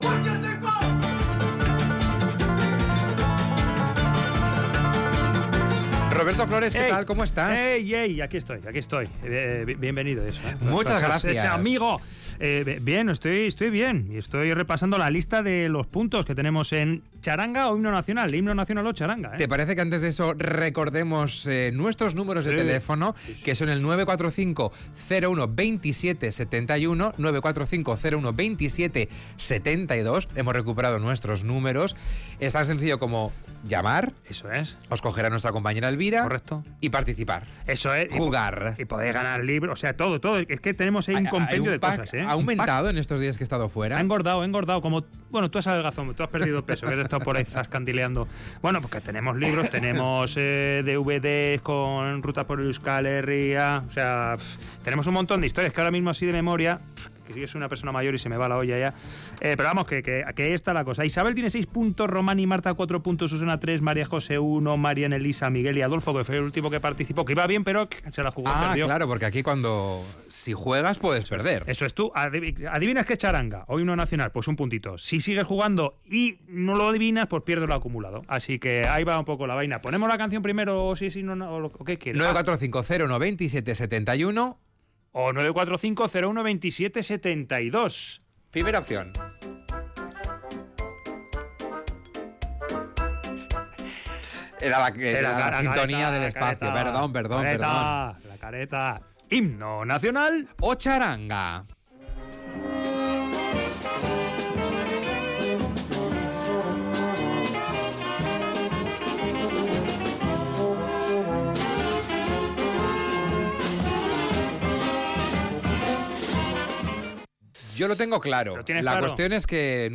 Audio: Acumula puntos adivinando si lo que escuchas es un himno nacional o la música de una txaranga.